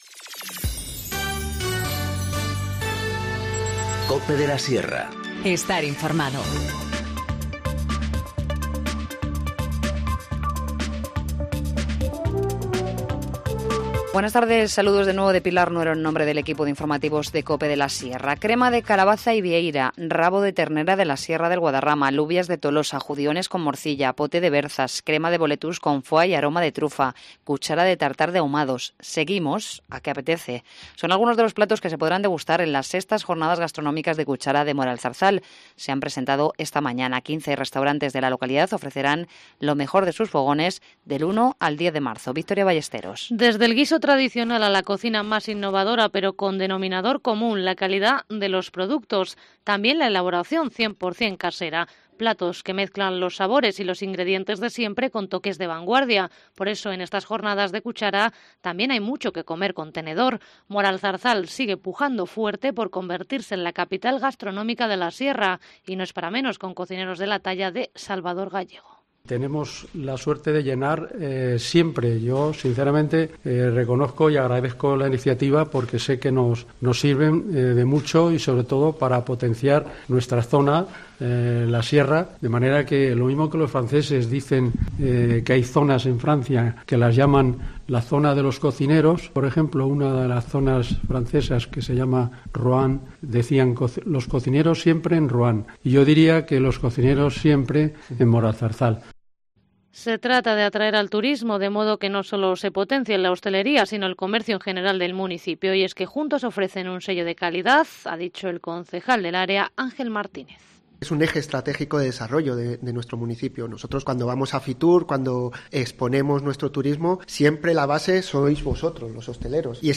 Informativo Mediodía 27 febrero-14:50h